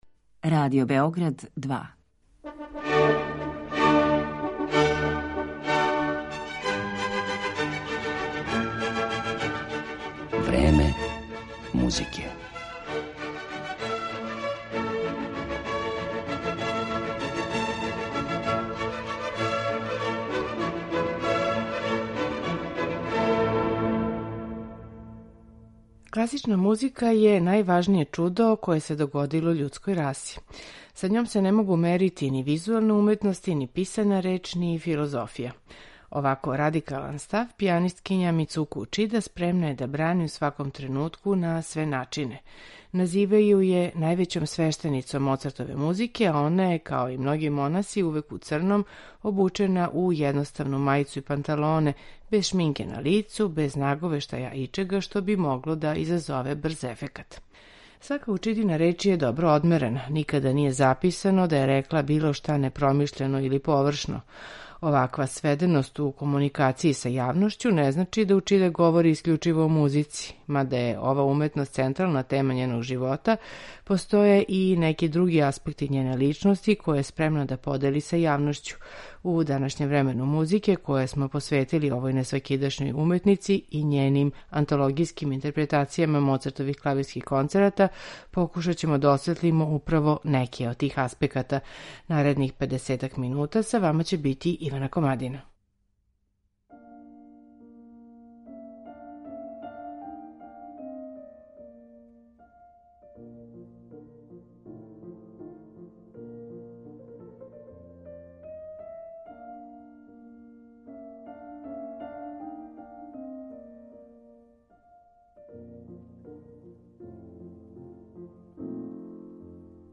клавирских концерата